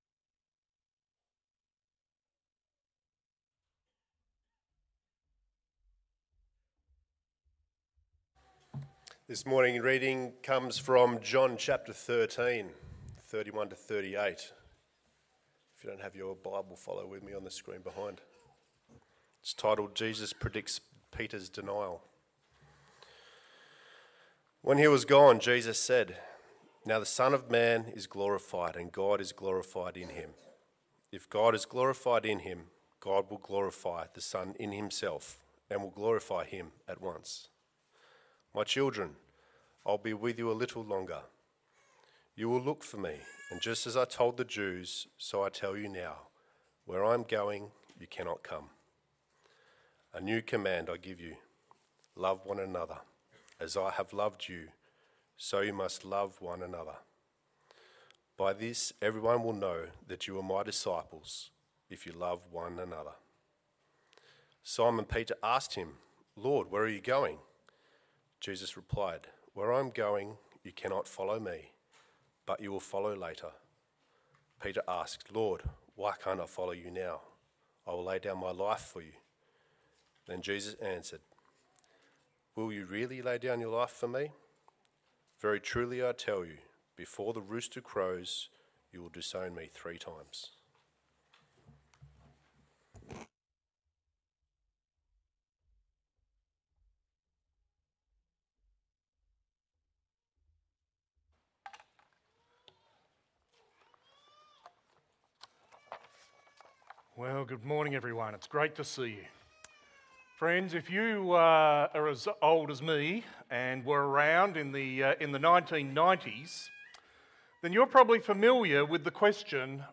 Sermons | Riverbank Christian Church